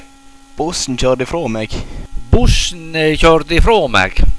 Denj karakteristiske ”o” lyden so bulenninganje av å te bruka i